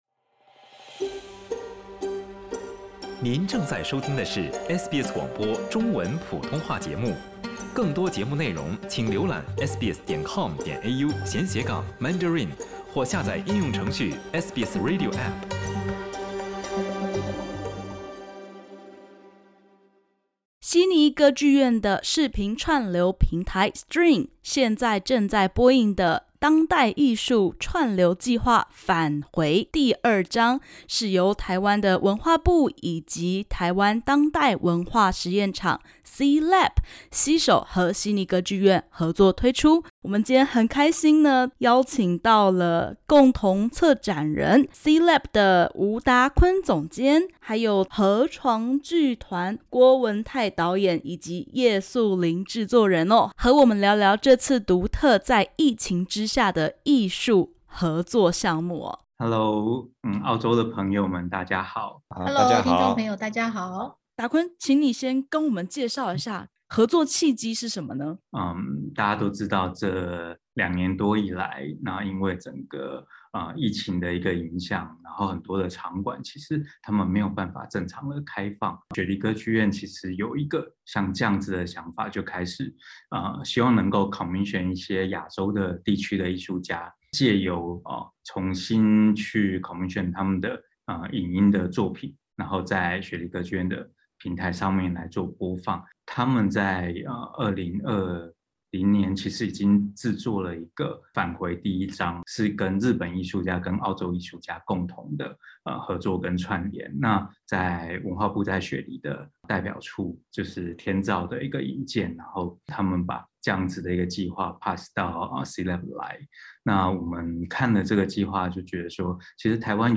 雪梨歌劇院與台灣藝文團隊所打造的「返回：第二章」影像作品展，現正於歌劇院線上平台Stream播映中。 （點擊首圖收聽採訪podcast）